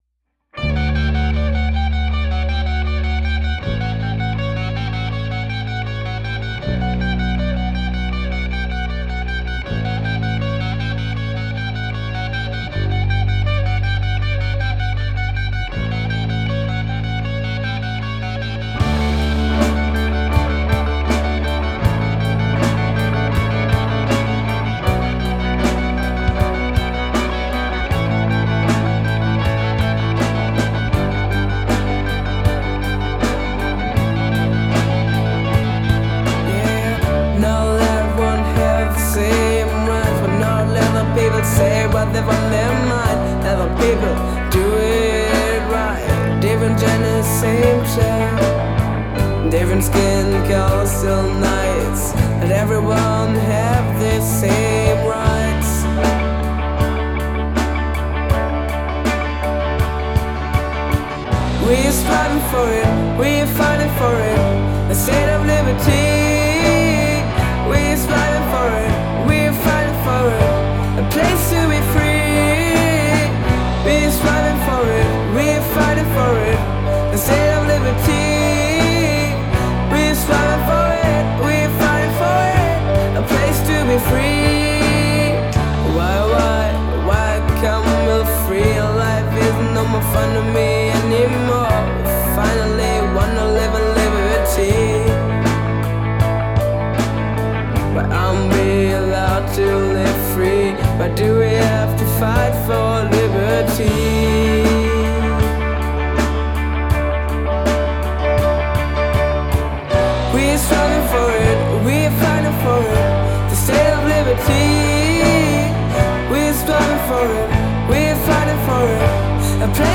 Mit ihrem neuen Song
Die Gruppe Room 9 Teen probt jeden Freitag um 15:15 Uhr neben dem SV-Raum.